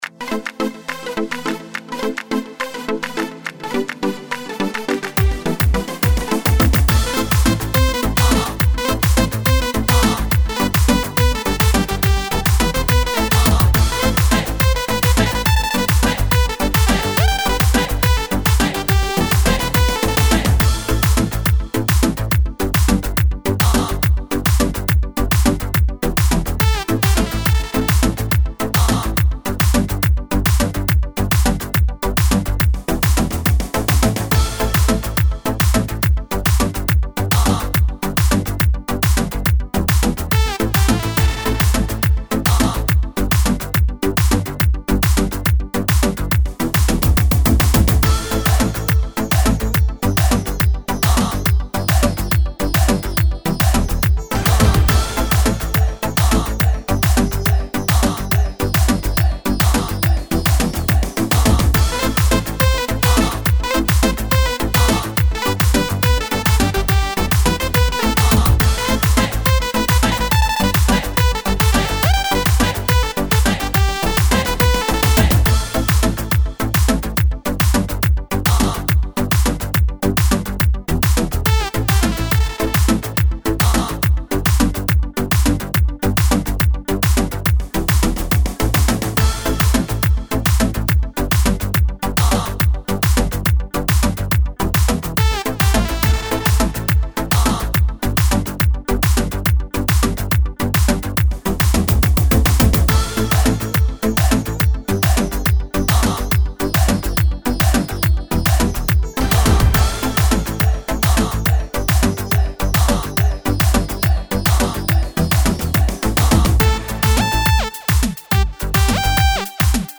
Жанр: Оргинал минуслар